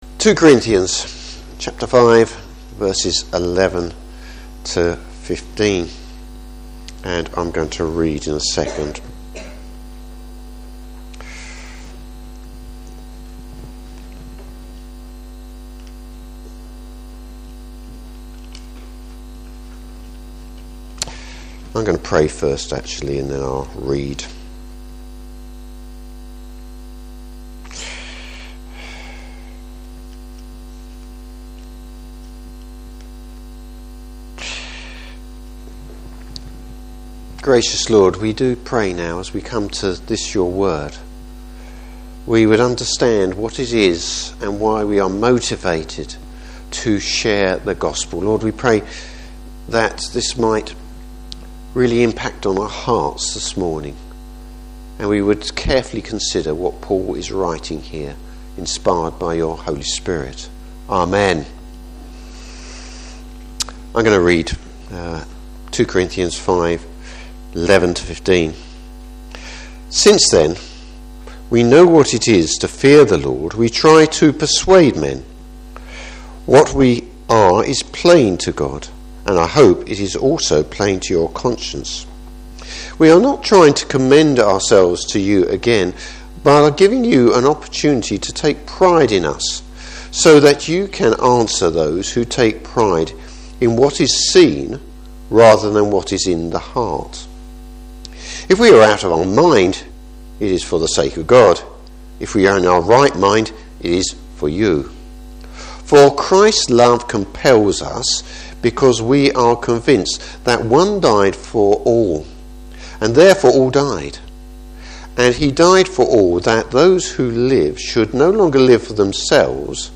Service Type: Morning Service How we are compelled by Christ’s love.